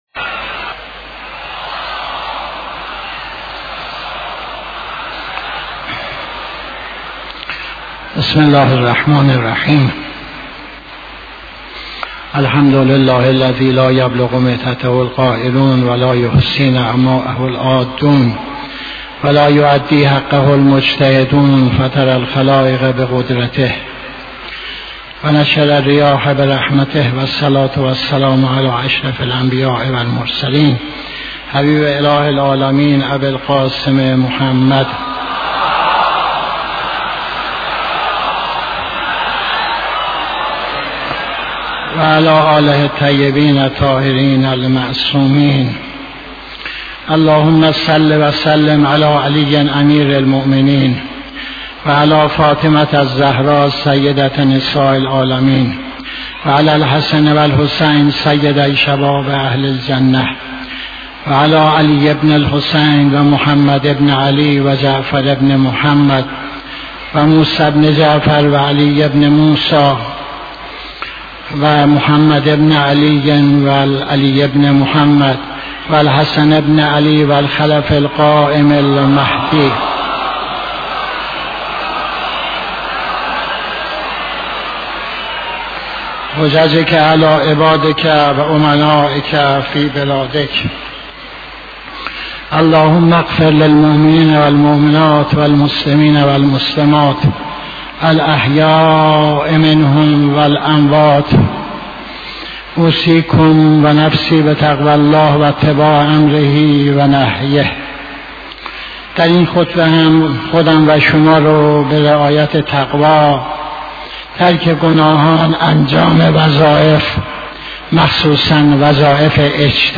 خطبه دوم نماز جمعه 22-02-85